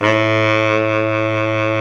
Index of /90_sSampleCDs/Roland L-CDX-03 Disk 1/SAX_Baritone Sax/SAX_40s Baritone
SAX B.SAX 03.wav